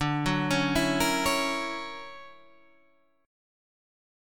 D+M9 chord